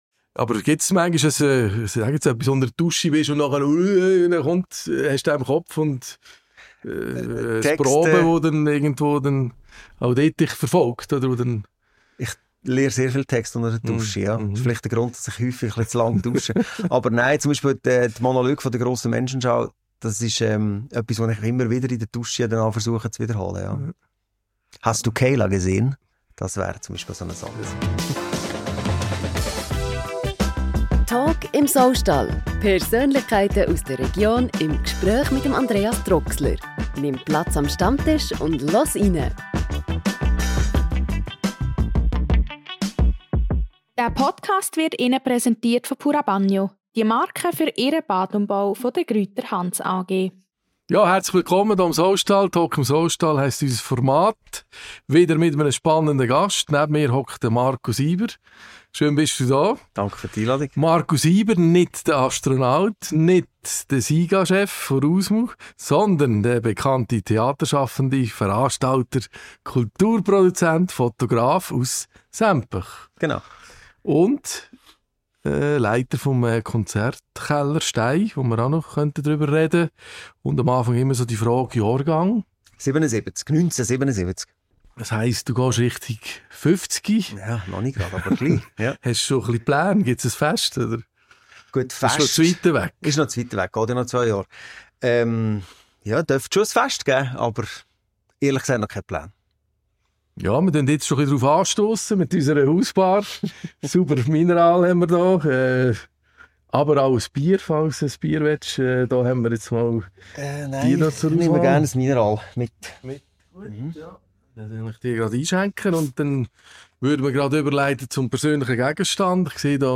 Im monatlichen Podcast im ehemaligen Saustall in Nottwil erfährst du mehr über die Menschen aus der Region.